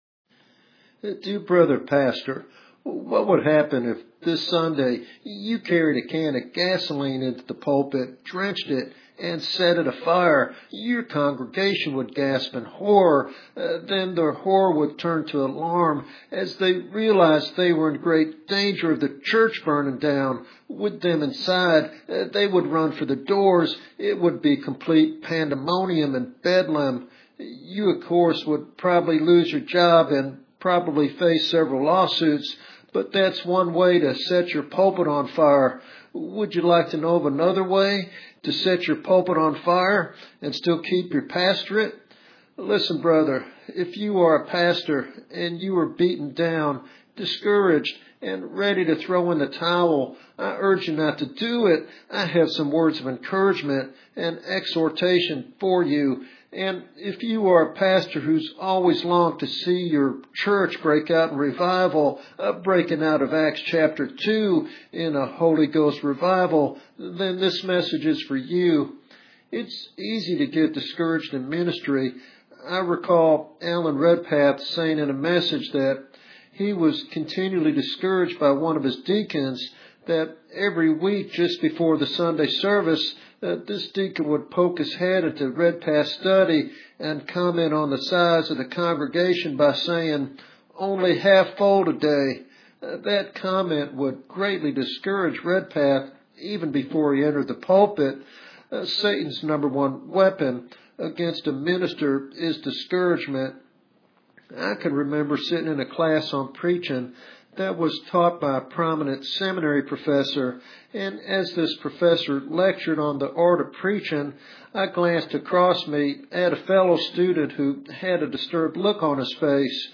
This teaching sermon encourages ministers to embrace the hard truths of Scripture and trust God for a transformative move of His Spirit.